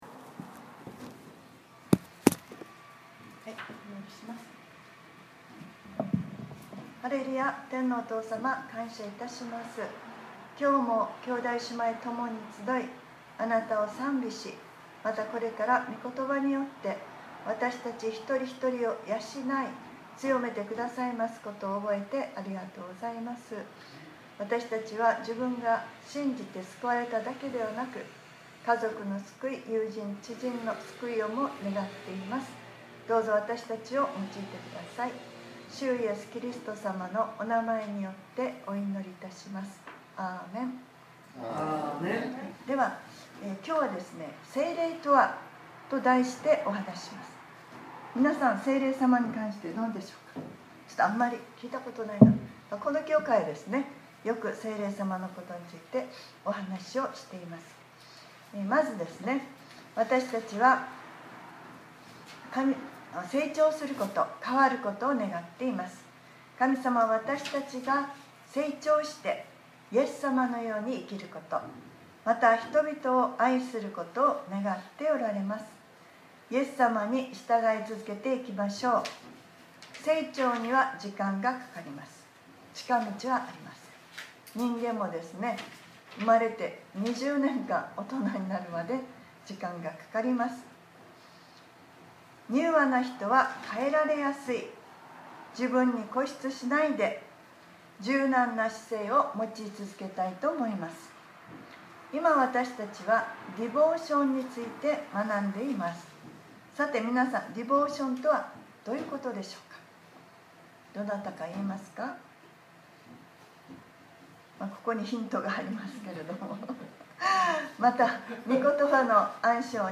2021年02月21日（日）礼拝説教『聖霊が共に』